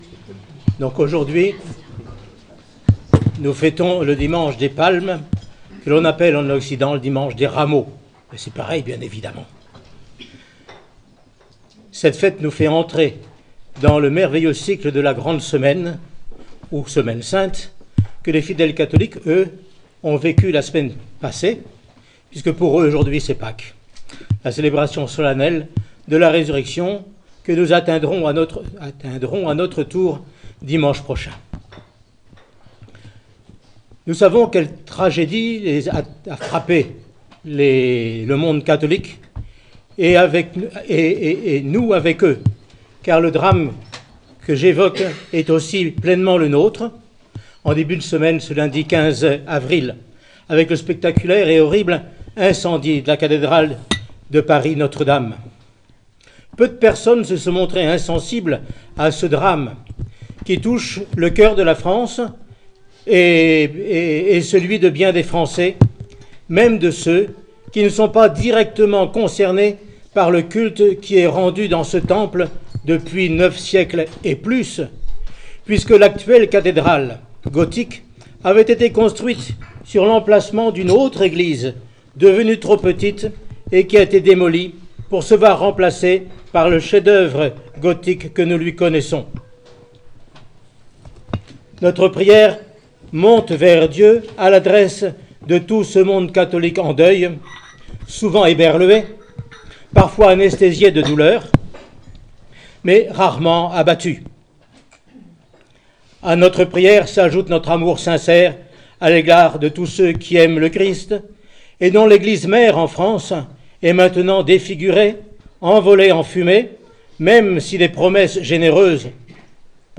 Homélie sur l’incendie de Notre-Dame de Paris :Monastère de la Transfiguration